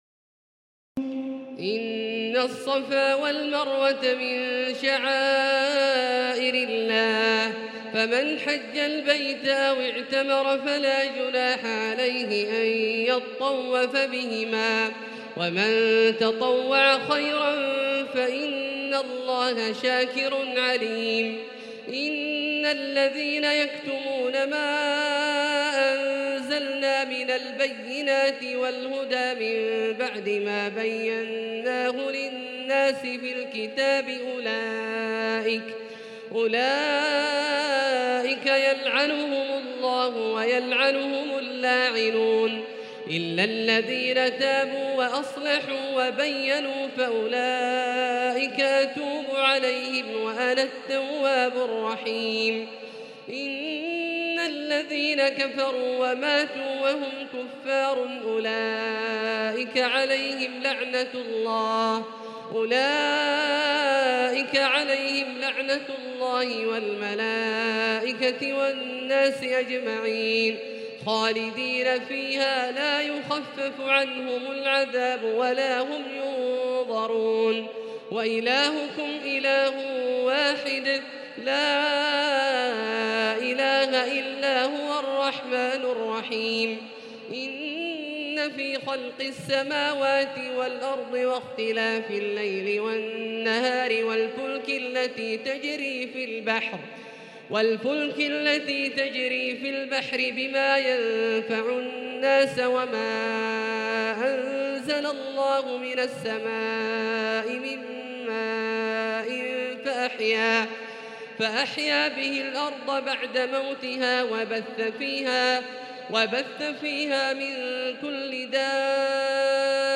تراويح الليلة الثانية رمضان 1438هـ من سورة البقرة (158-218) Taraweeh 2 st night Ramadan 1438H from Surah Al-Baqara > تراويح الحرم المكي عام 1438 🕋 > التراويح - تلاوات الحرمين